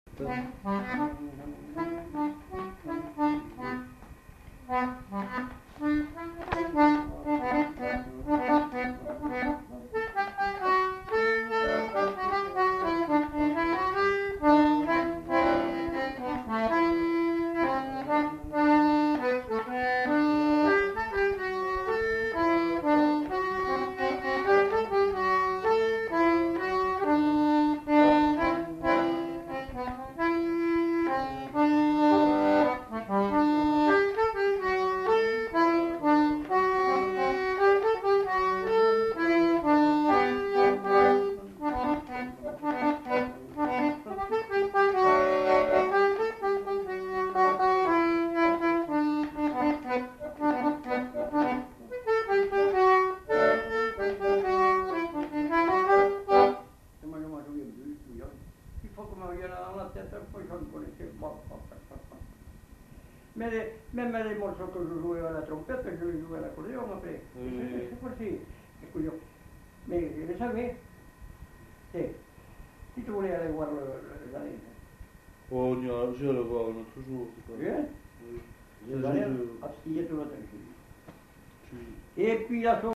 Répertoire d'airs à danser du Marmandais à l'accordéon diatonique
enquêtes sonores
Marche